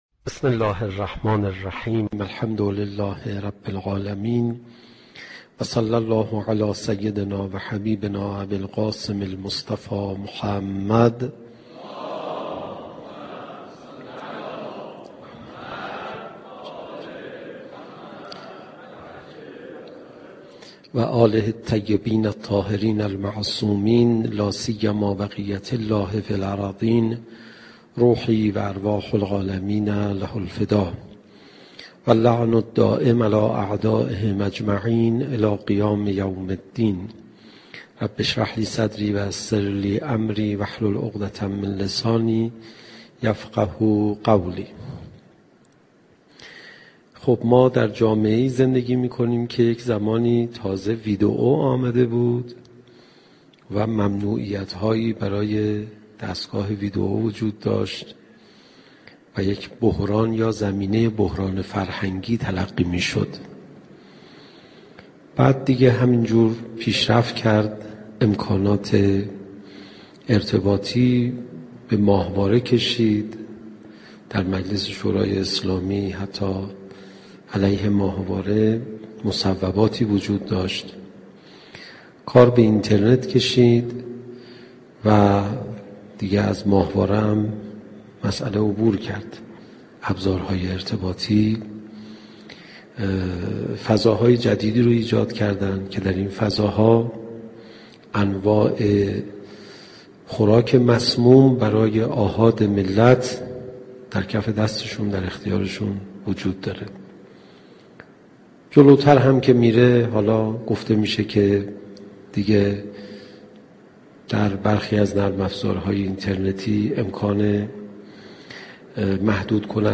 سخنرانی حجت الاسلام علیرضا پناهیان با موضوع قدرت انسان در برابر ابزارهای نوین بندگی